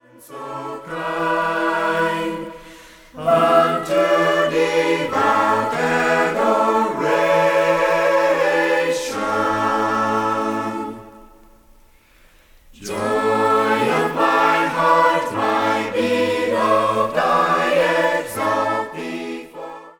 A cappella mixed group sings many beautiful hymns.